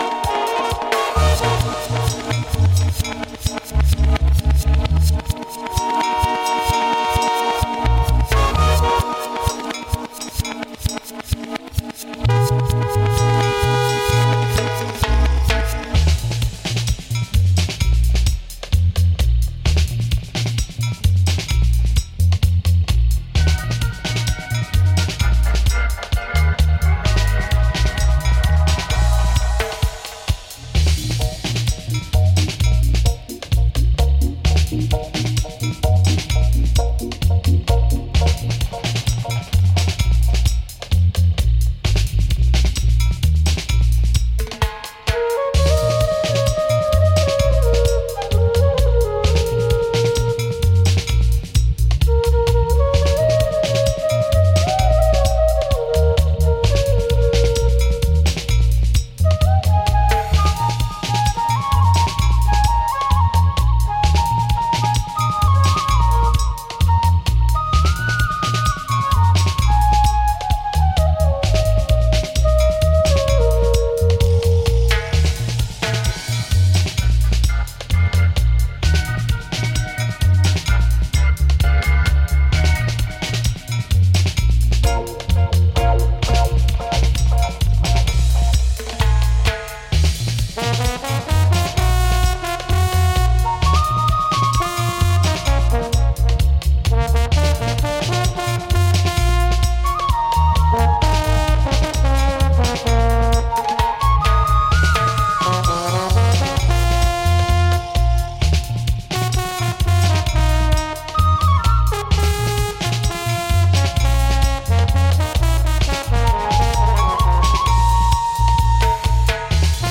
Reggae / Dub